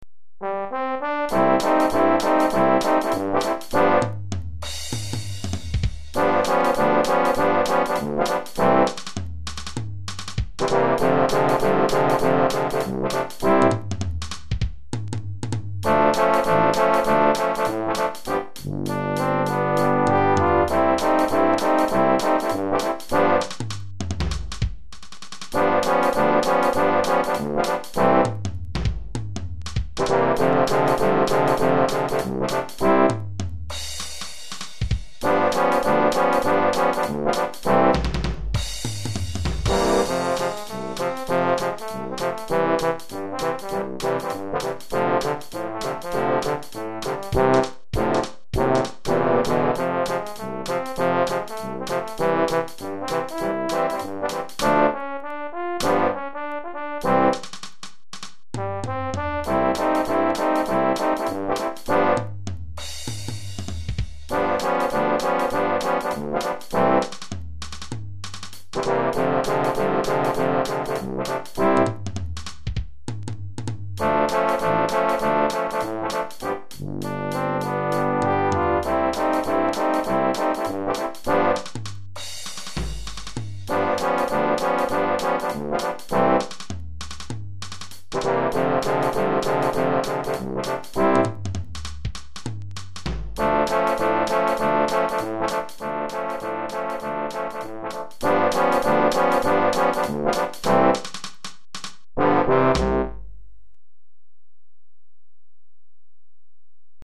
Quintette de Trombones et Batterie